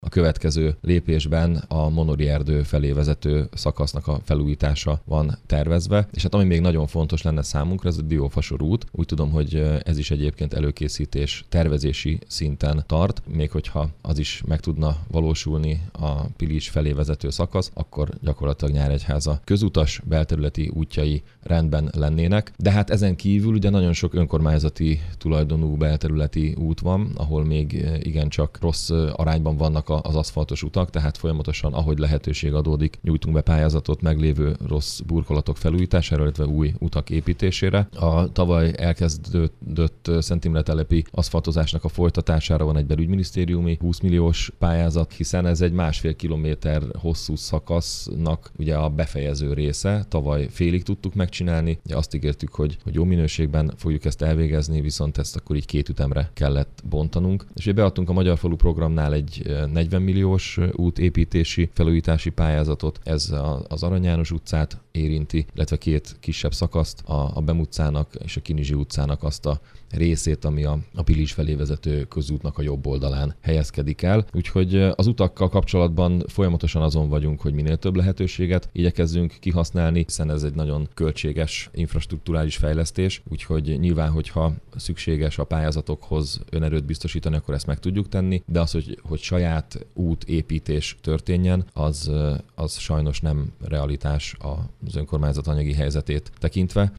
Az önkormányzati utak esetében még sok a munka, mondta Mészáros Sándor polgármester.